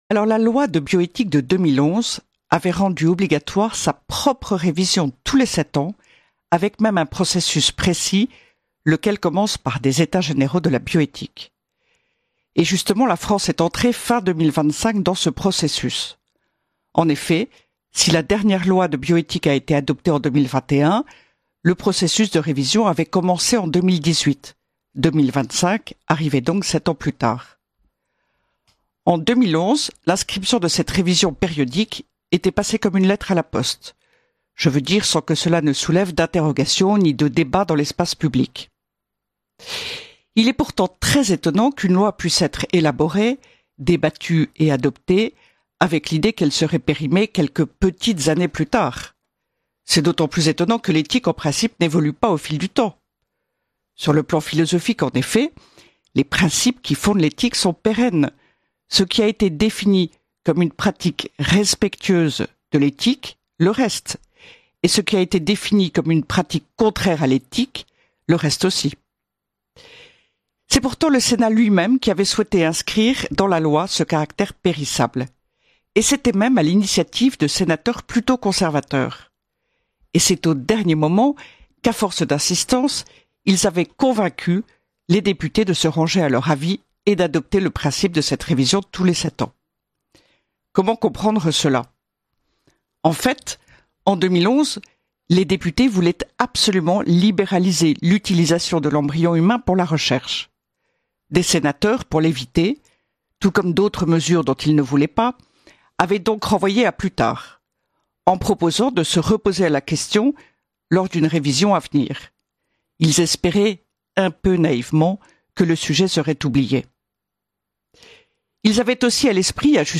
Esprit de Famille : Retrouvez chaque semaine la chronique de Ludovine de La Rochère, diffusée le jeudi sur Radio Courtoisie, pour connaître et comprendre l’essentiel de l’actualité qui concerne la famille